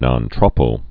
(nŏn trôpō, nōn trōppō)